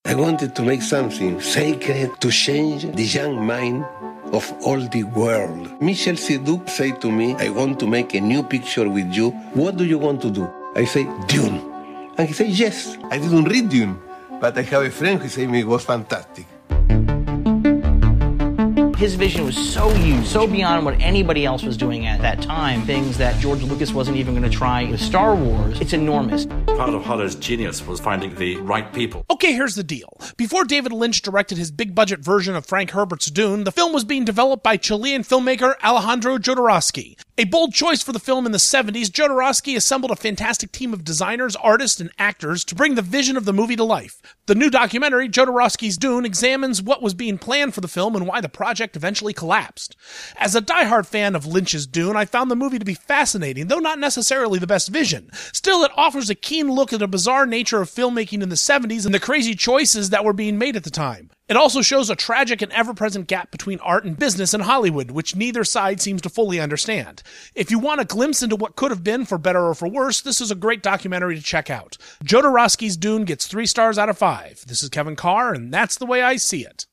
‘Jodorowsky’s Dune’ Movie Review